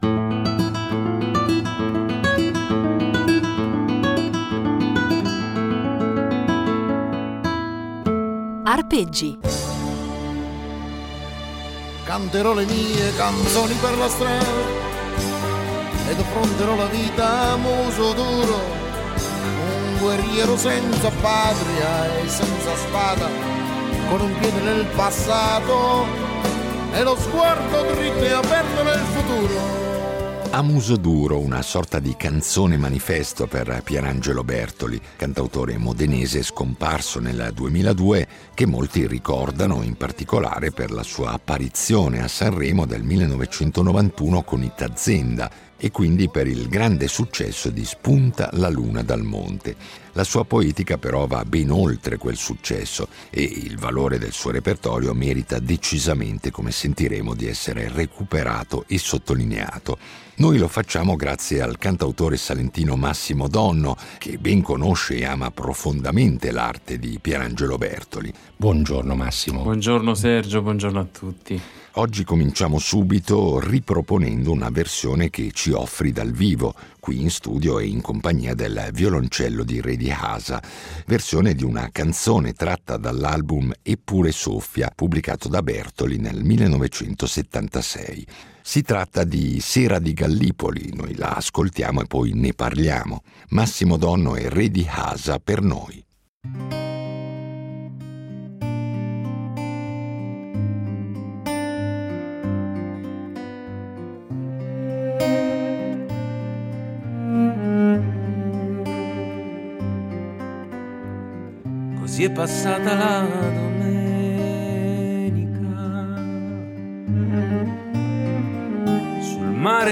violoncellista